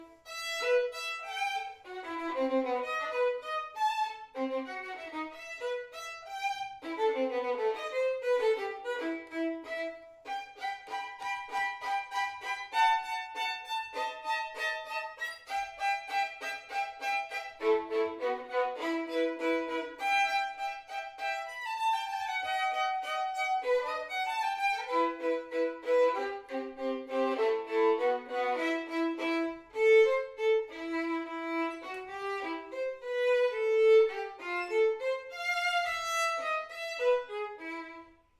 Diese führe sie solo oder mit Band-Besetzung auf.